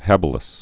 (hăbə-ləs)